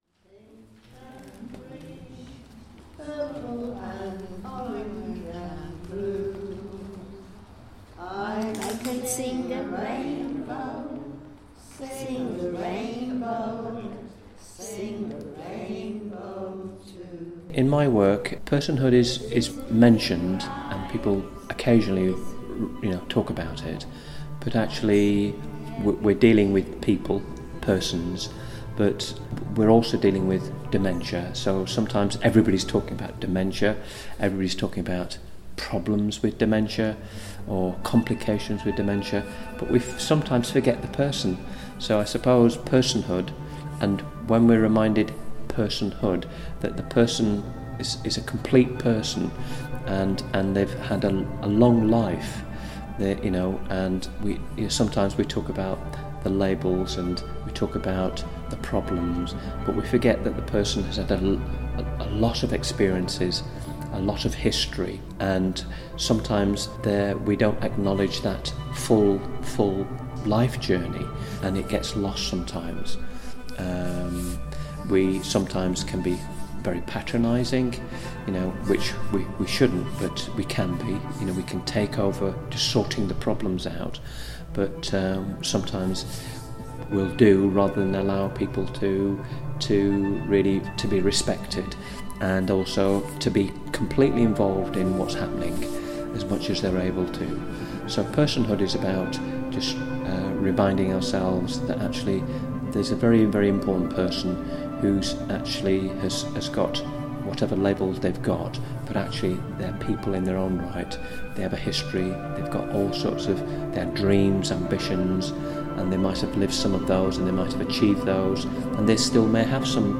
Theatre of Wandering Creative Soundscapes #8
Created by sound artist